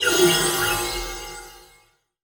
Spell_00.wav